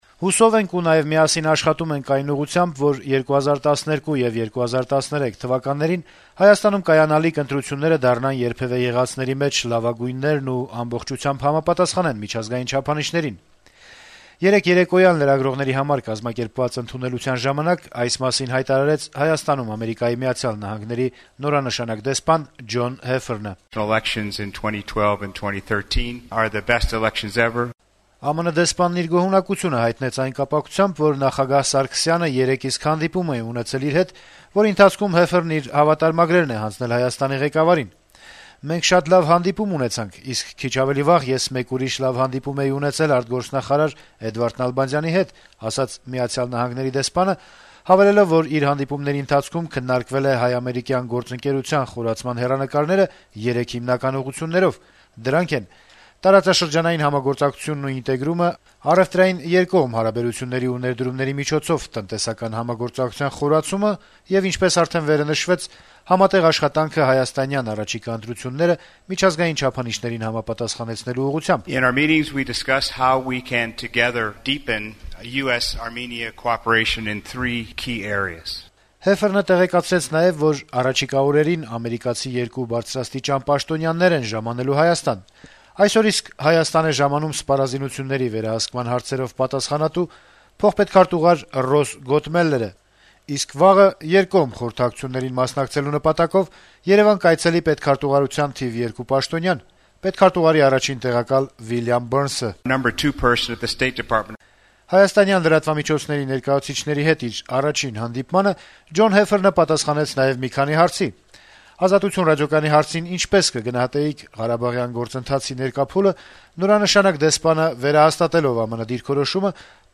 Այդ մասին ԱՄՆ-ի նորանշանակ դեսպանը ասաց Հայաստանի լրագրողների հետ առաջին հանդիպմանը։